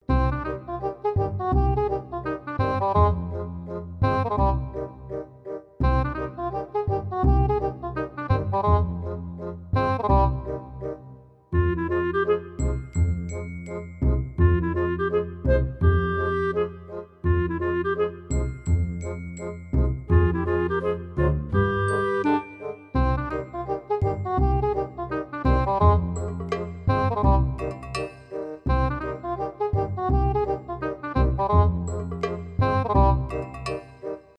инструментальные , интригующие , веселые
загадочные , без слов